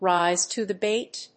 アクセントríse to the báit